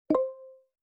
Teams 弹拨.mp3